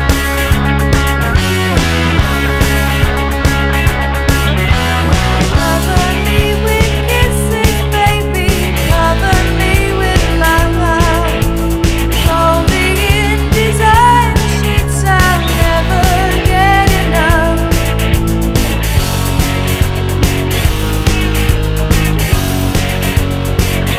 No Guitars Pop (1980s) 3:31 Buy £1.50